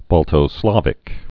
(bôltō-slävĭk, -slăvĭk)